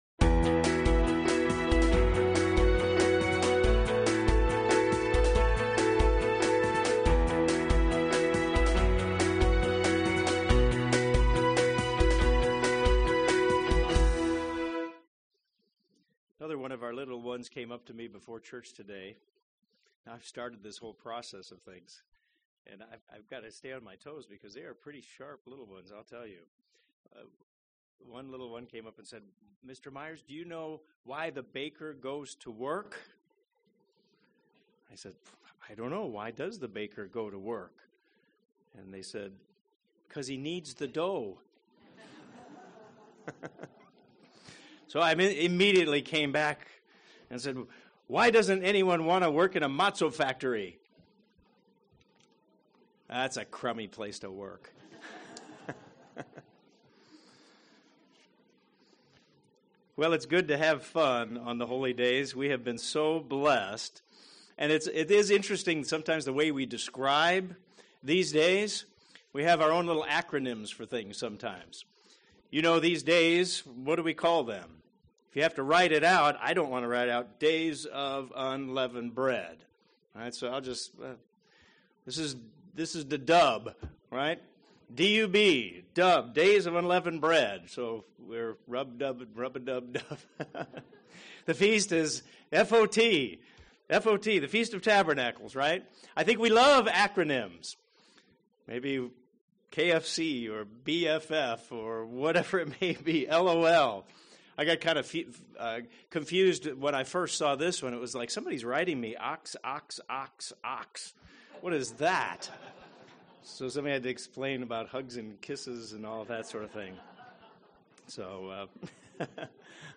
Are we being wise stewards of God's investment in us? This sermon was given on the First Day of Unleavened Bread.